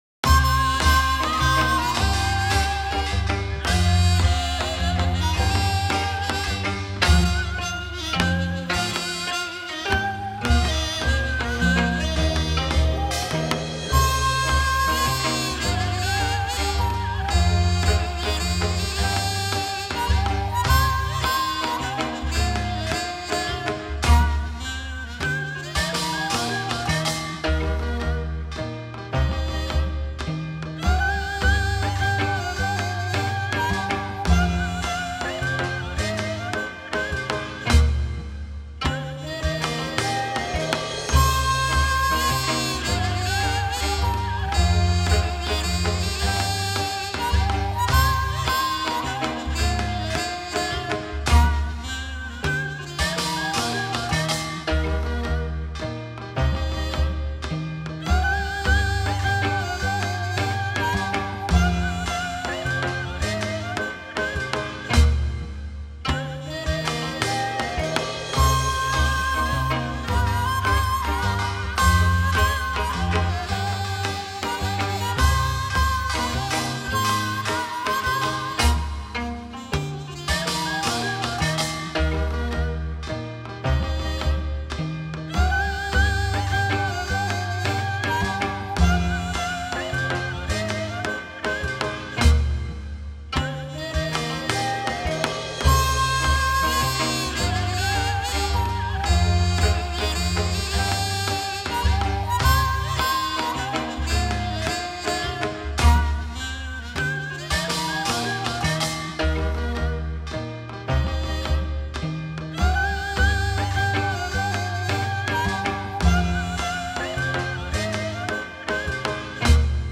반주음악(MR)